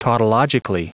Prononciation du mot tautologically en anglais (fichier audio)
tautologically.wav